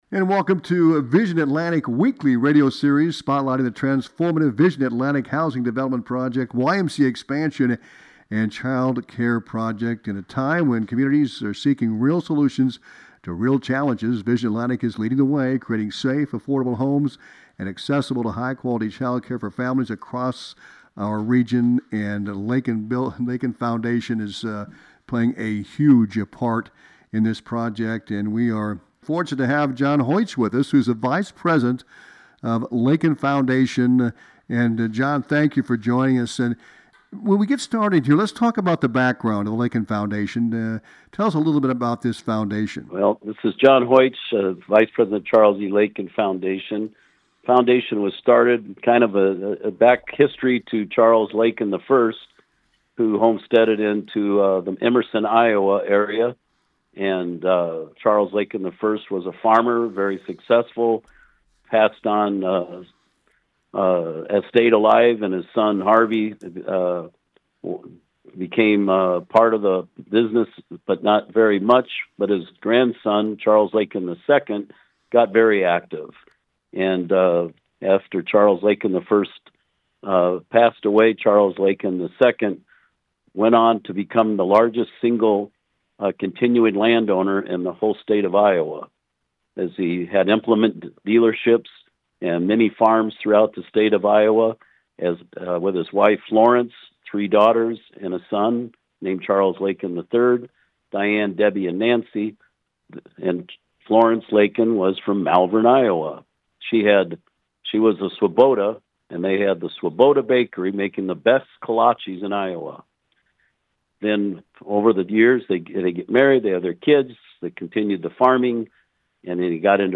The entire interview